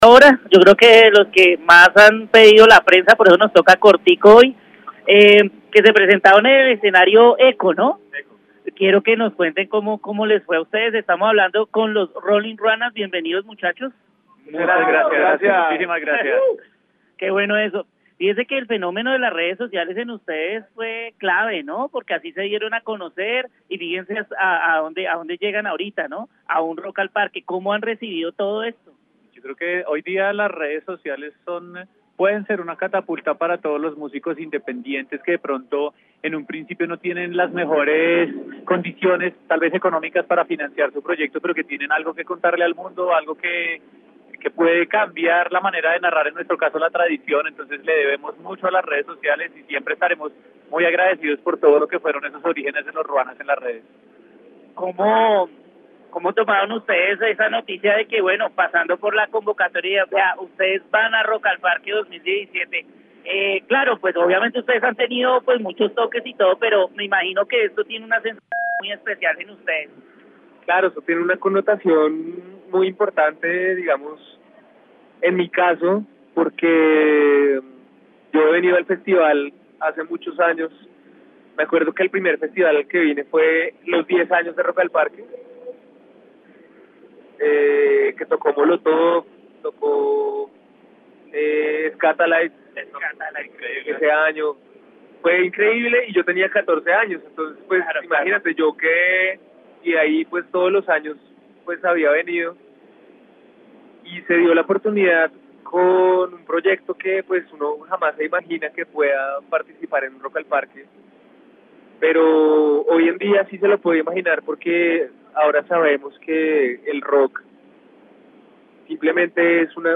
interpretados con instrumentos propios de la carranga